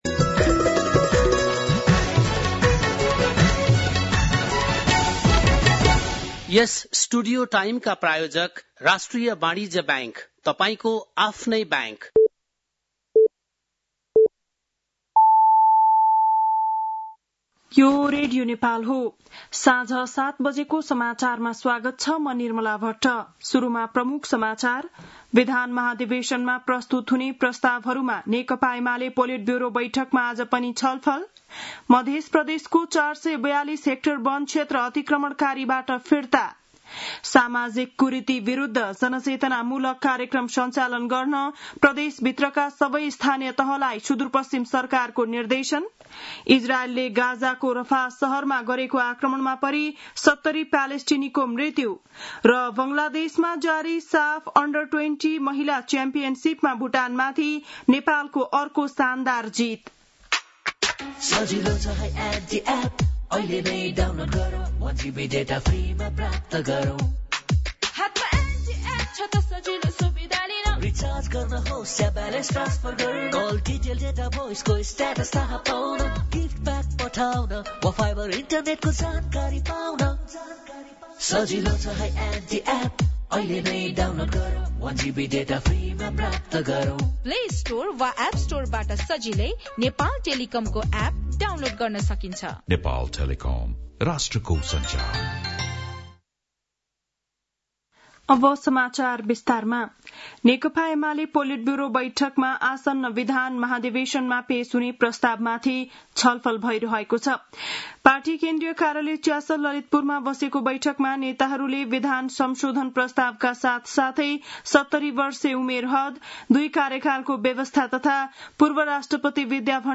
बेलुकी ७ बजेको नेपाली समाचार : ३ साउन , २०८२